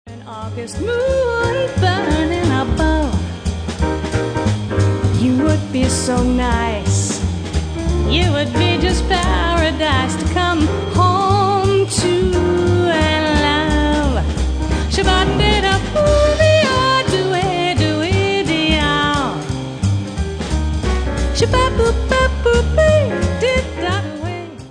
Vocals
Piano
Double Bass
Drums